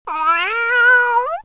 Long Meow Bouton sonore